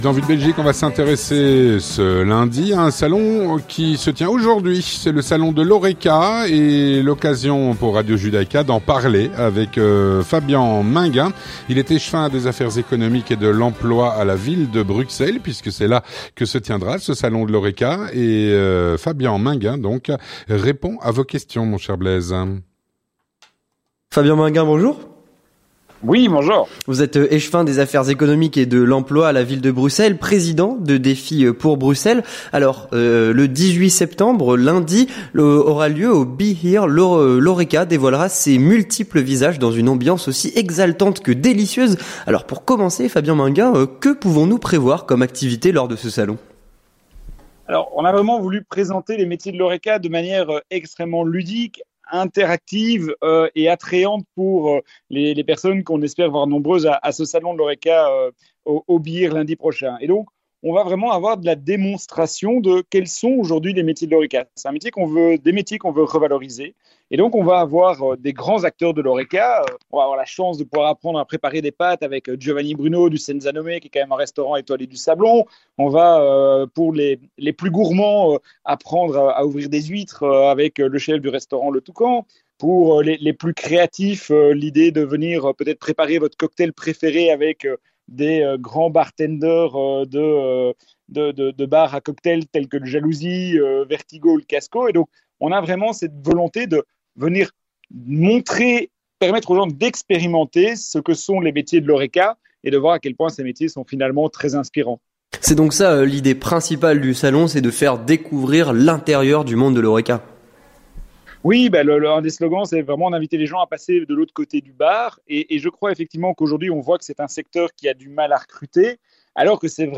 Avec Fabian Maingain, échevin des affaires économiques et de l'emploi à la ville de Bruxelles.